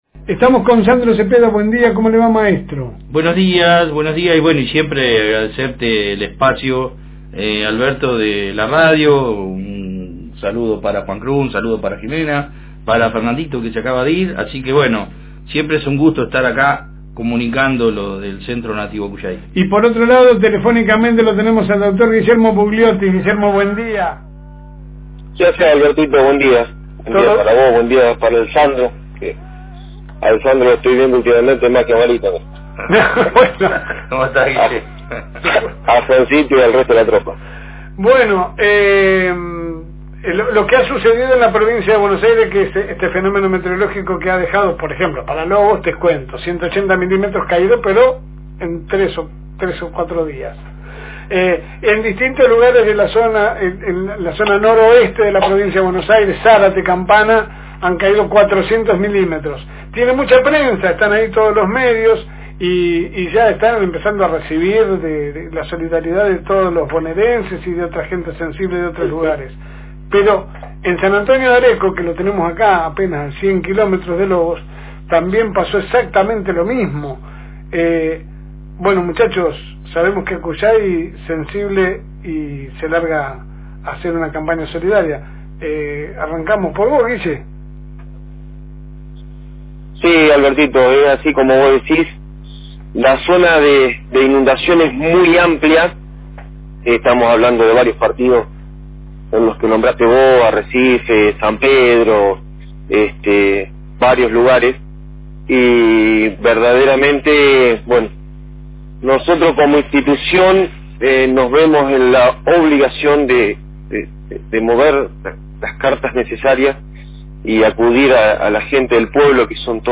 en una entrevista concedida a FM Reencuentro 102.9